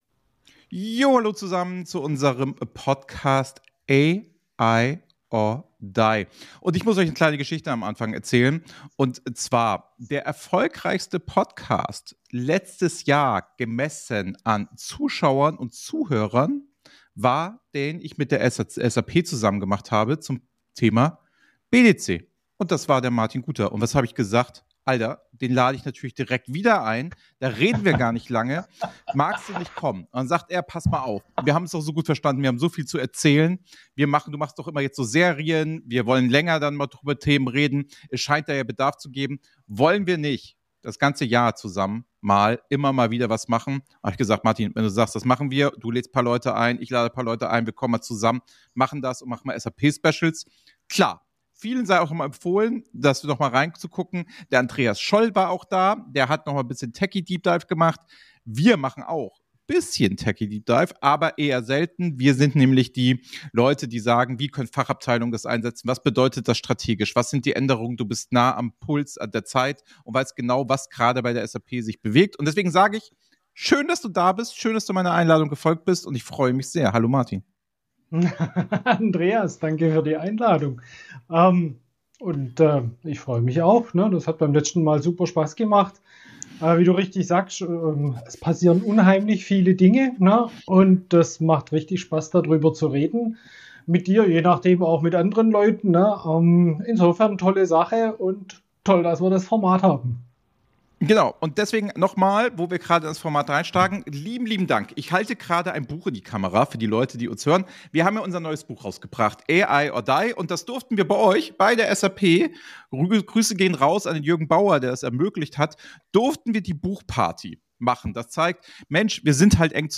Ein ehrliches, tiefes und praxisnahes Gespräch über Strategie, Technologie und Vertrauen – mit Blick hinter die Kulissen der SAP-Welt.